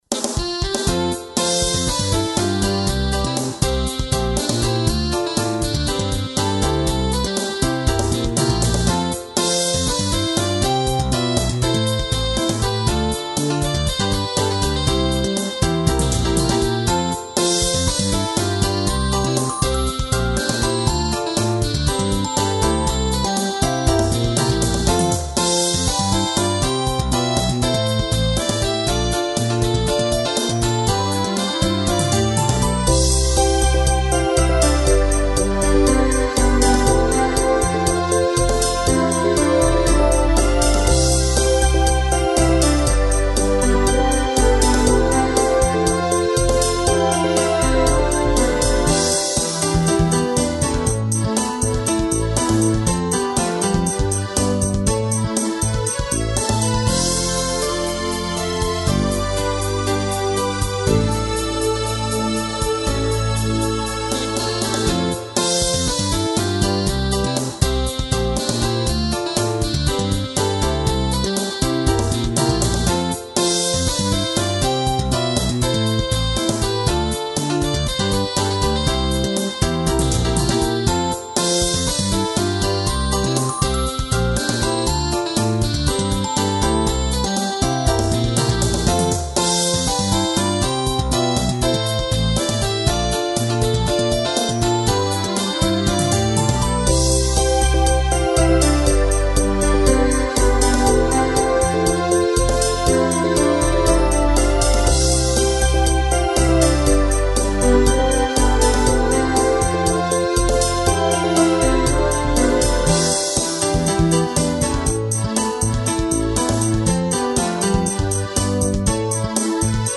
こちらでは、ゲーム内で使われているＢＧＭを何曲か、お聴かせしちゃいます。
由緒のイメージテーマ。
聞いた通りの、元気で人なつっこい娘です。